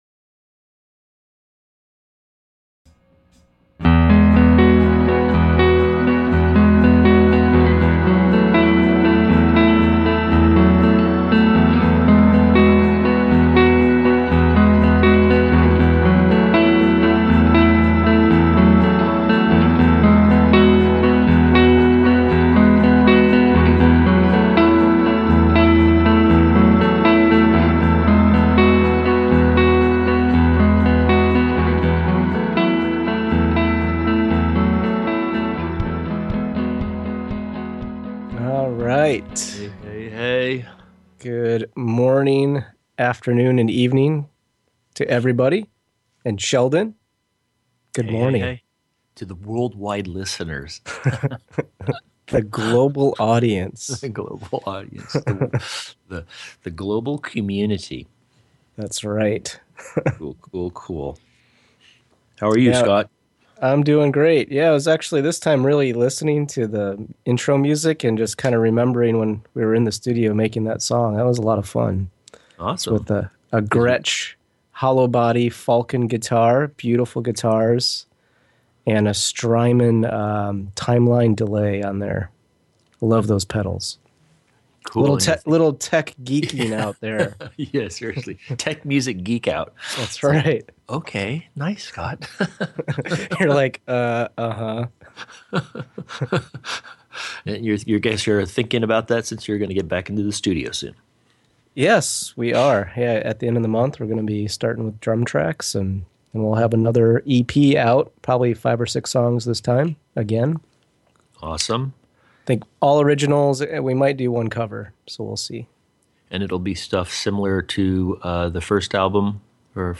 Talk Show Episode, Audio Podcast
The show starts with a guided meditation connecting you to Spirit and allowing you to transform all of the special relationships in your life.
Of Course Radio is a lively broadcast focused on the messages within ‘A Course in Miracles’ as well as in-depth explorations into how we live forgiveness in our daily lives and remember our connection with God/Source.